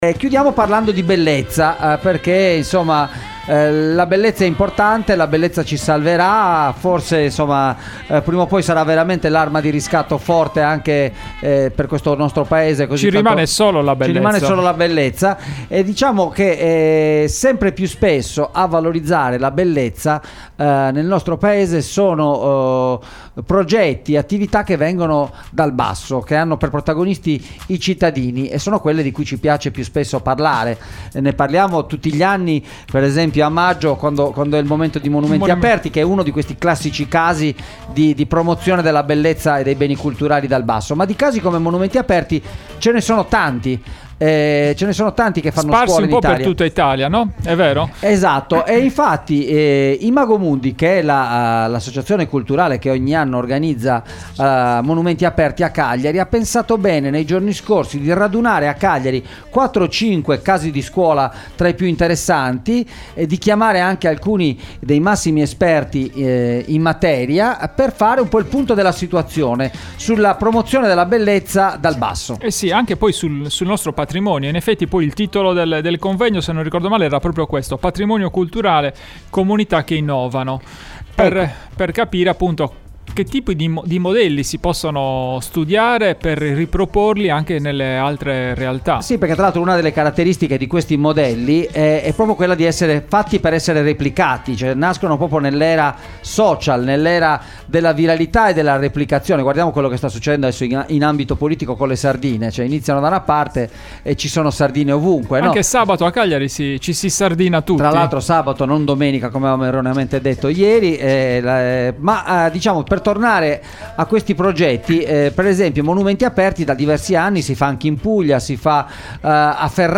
patrimoniocult_interviste312.mp3